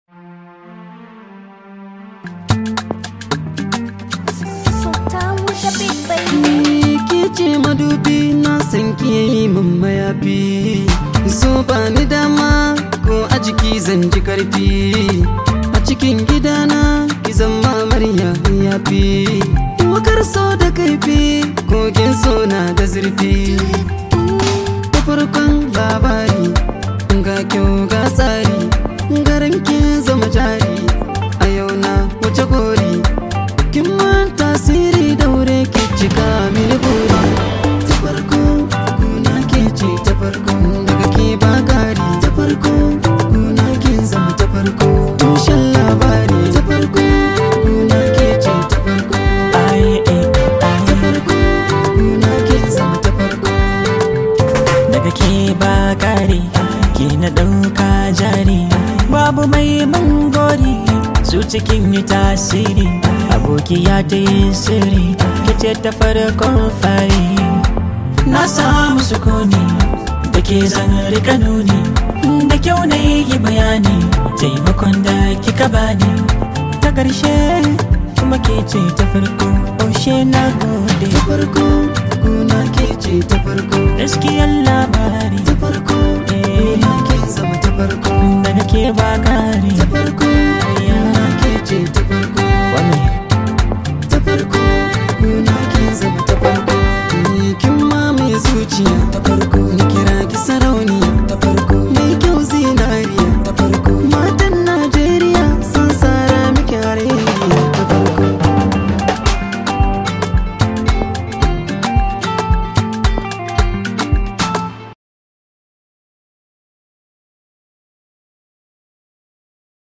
Hausa Songs